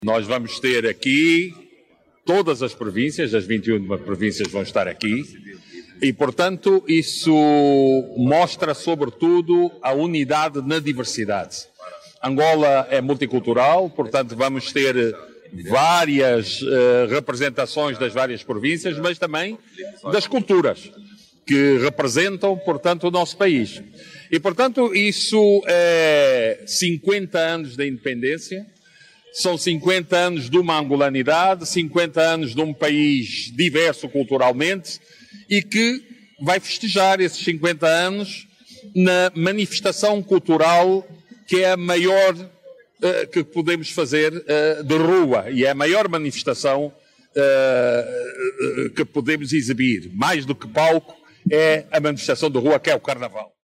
O Ministro da Cultura, Filipe Zau, disse que o desfile, de carácter não competitivo vai representar a unidade na diversidade.